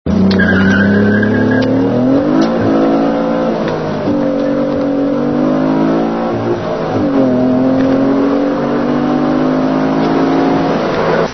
Hi quailty sound file recorded from inside the car, windows closed.
windowsclosed.mp3